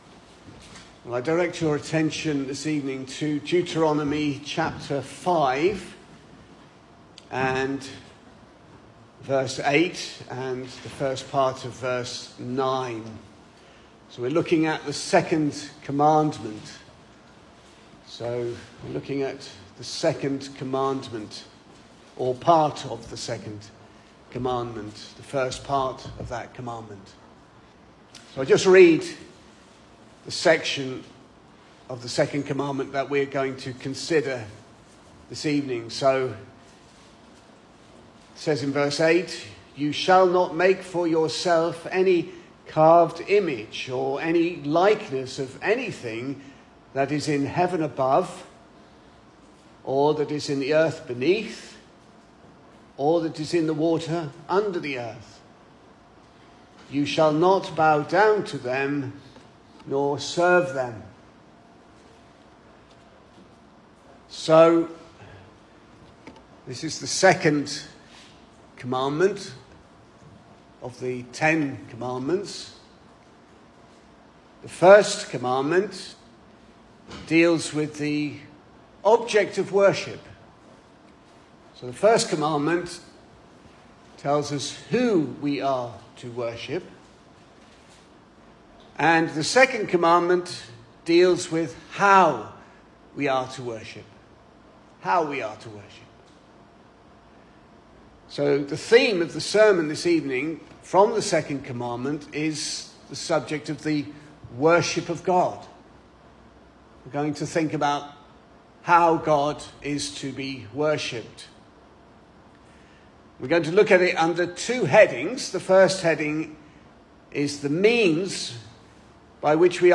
Service Type: Sunday Evening
Series: Single Sermons